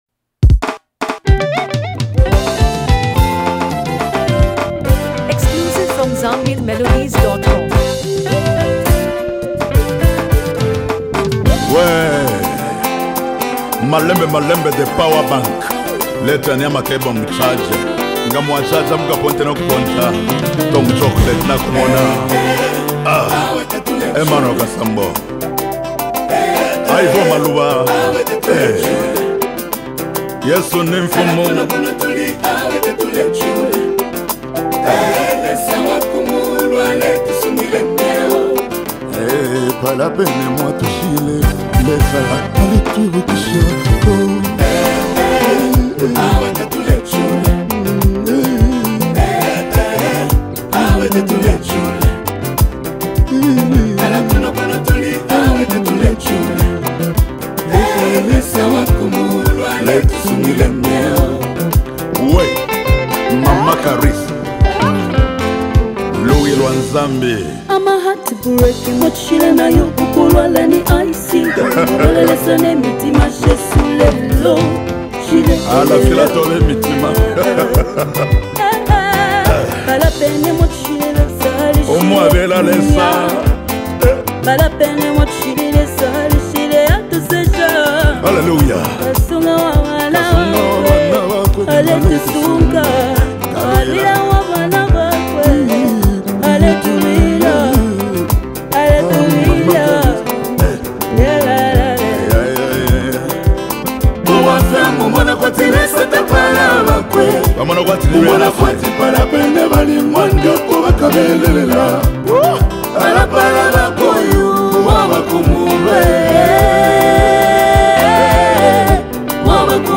Zambian Music
and signature Kalindula-infused gospel sound
commanding delivery, backed by harmonious vocals